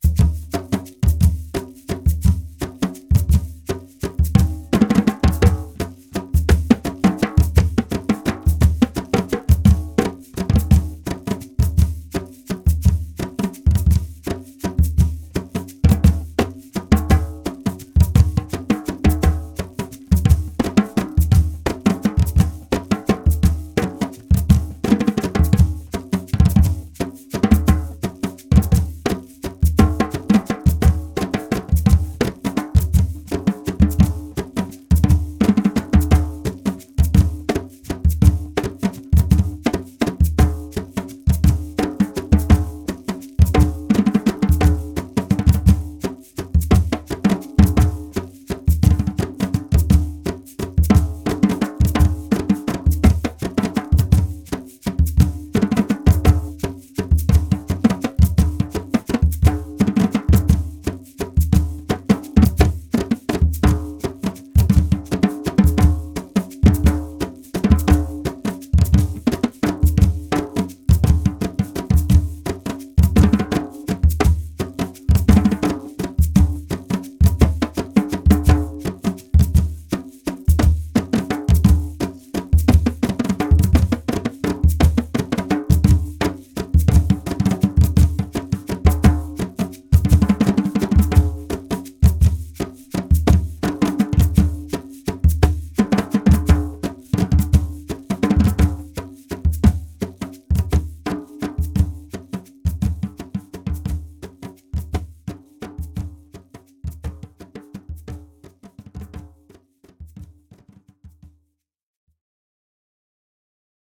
Localisation Sinnamary
Résumé instrumental
danse : grajévals (créole)
Pièce musicale inédite